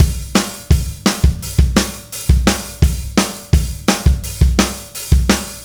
Indie Pop Beat 03.wav